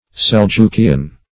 Seljukian \Sel*juk"i*an\